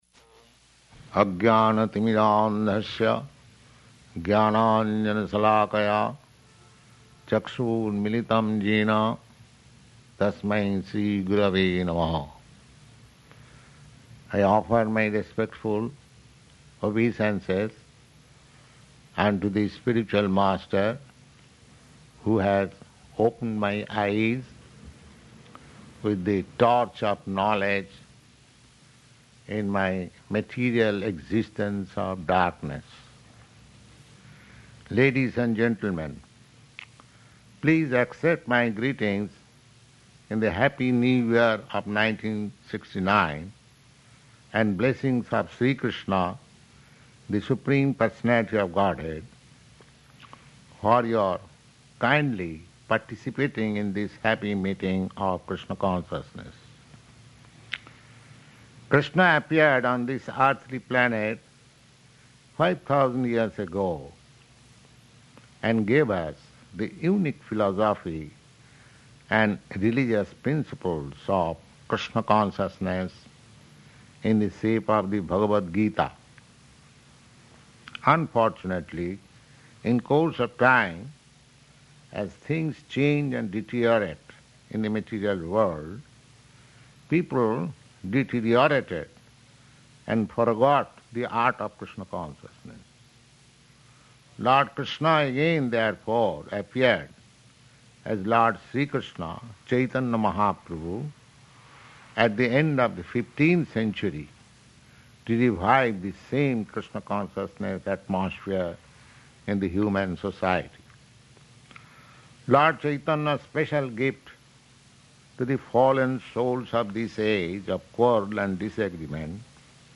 Recorded Speech to Members of ISKCON London
Type: Lectures and Addresses
Location: Los Angeles